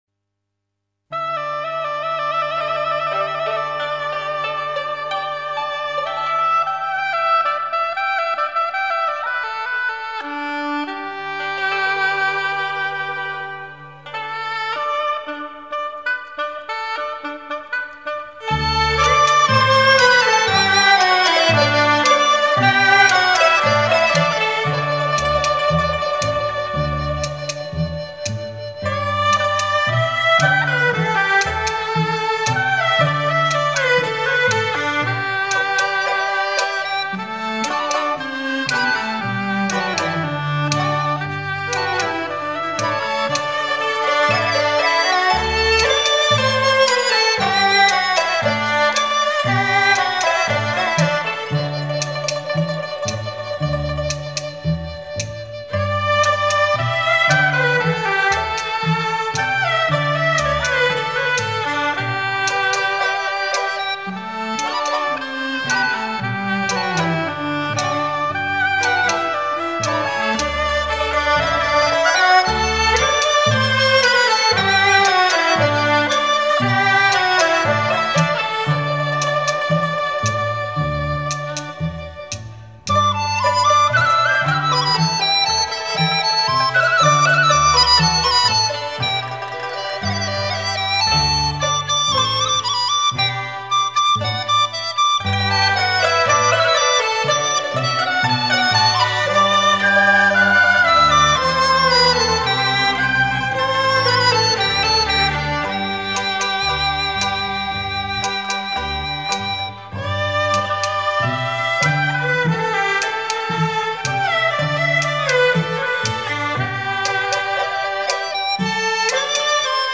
[21/11/2010]民樂合奏--《秋收》(192k/mp3) 激动社区，陪你一起慢慢变老！